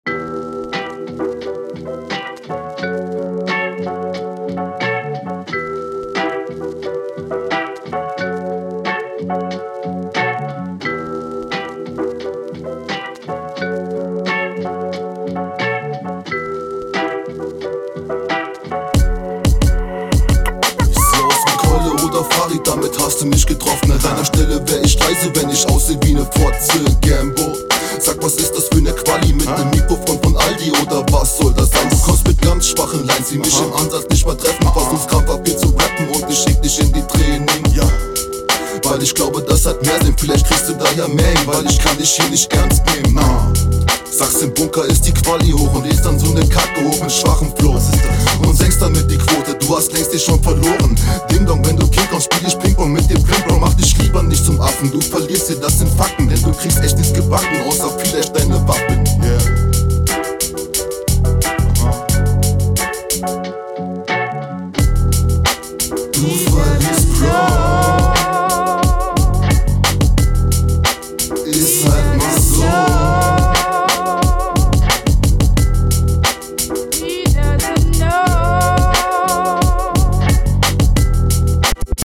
Die Qualität ist sehr viel höher, klingt schon um einiges angenehmer bei dir.